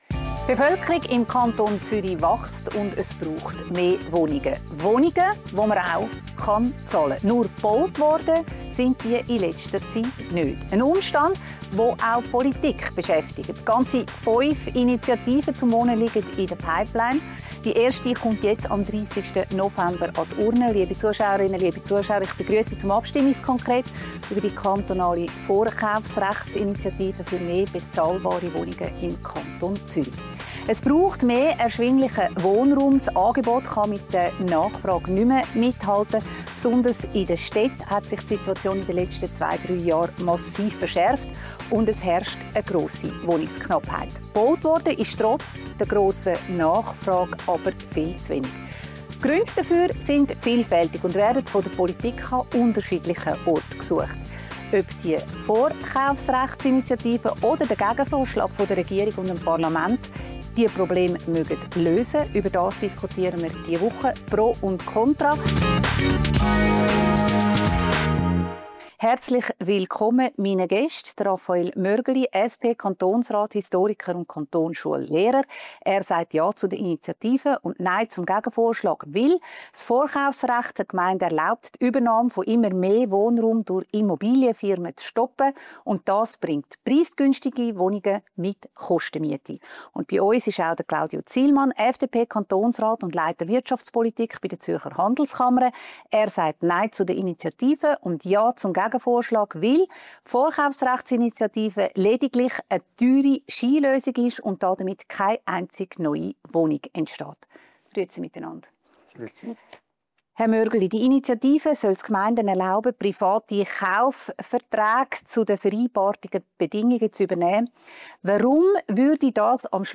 Zu Gast sind die beiden Zürcher Kantonsräte Rafael Mörgeli SP und Claudio Zihlmann FDP.